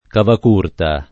[ kavak 2 rta ]